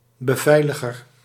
Ääntäminen
US : IPA : [gɑː(r)d] US : IPA : /ɡɑɹd/ UK : IPA : /ɡɑːd/